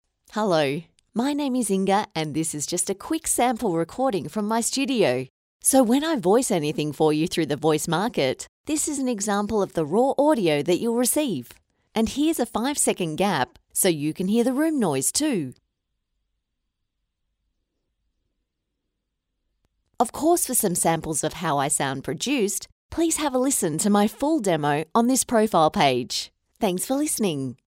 I’m an authentic Australian female voiceover artist with over 18 years of experience.
I can provide a quality studio recording from my home studio with a Rode NT1-A microphone and Adobe Audition editing software.
• Studio Sound Check